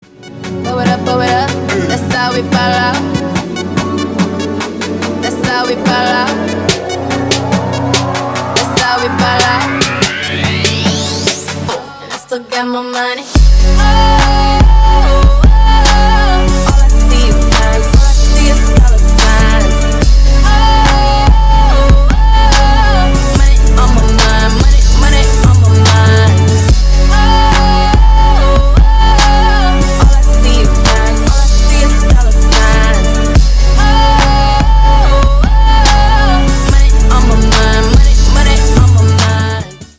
Ремикс # Электроника